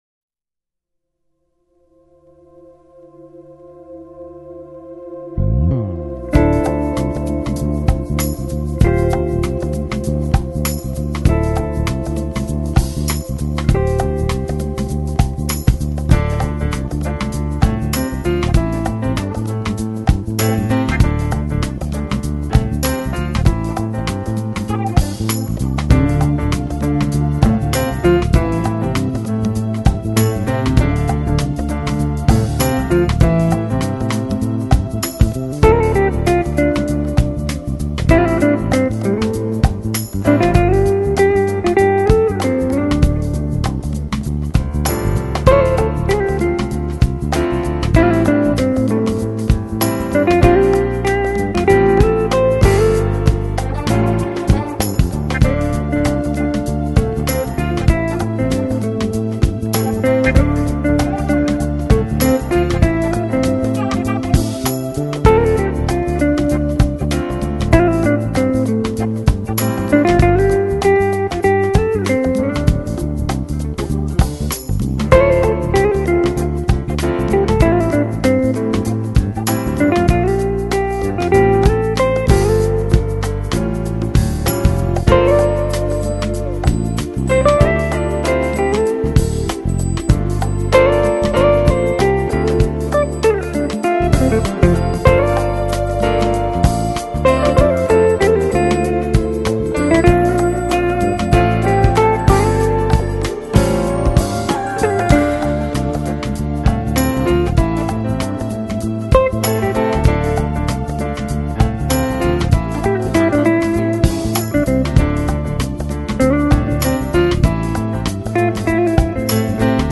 Genre: Soul/Smooth-Jazz
guitar, lead vocal
piano, keyboards